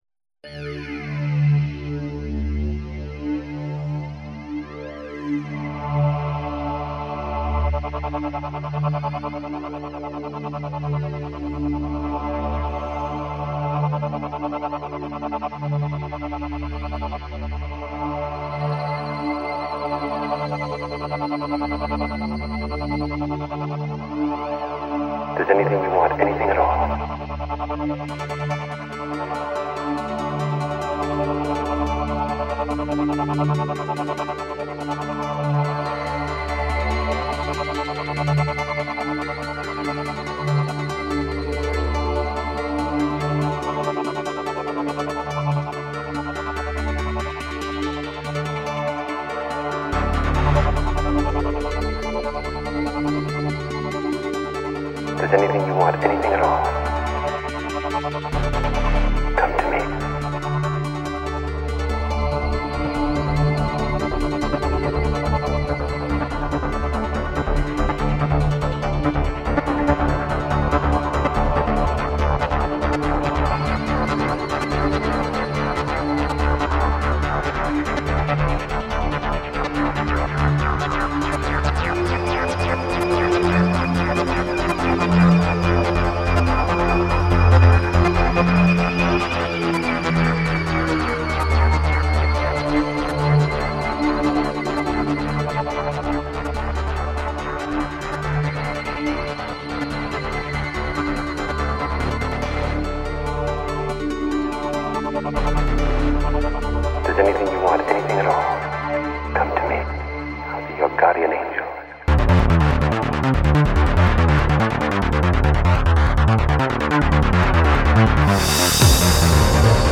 и исполняющий электронную музыку с элементами world music.
Он хотел создать экспериментальную музыку.